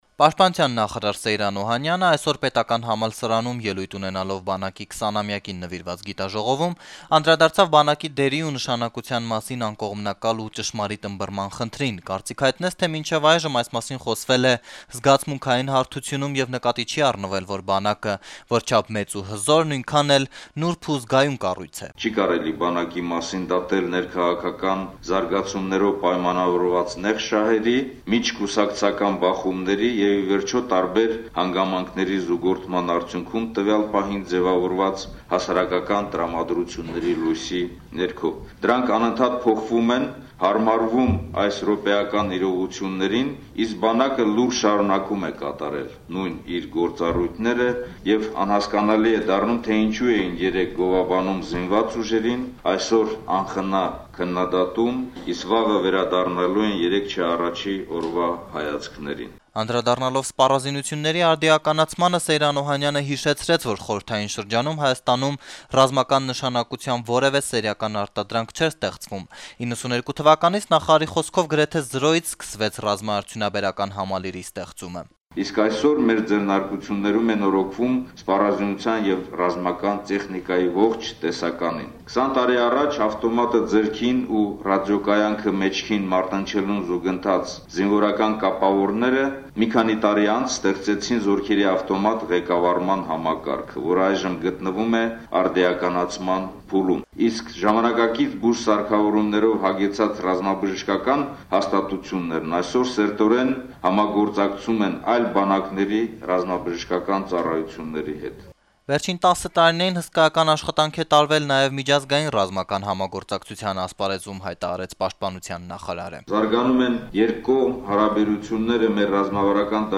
Պաշտպանության նախարար Սեյրան Օհանյանը ելույթ է ունեցել ԵՊՀ-ում բանակի 20-ամյակին նվիրված գիտաժողովում: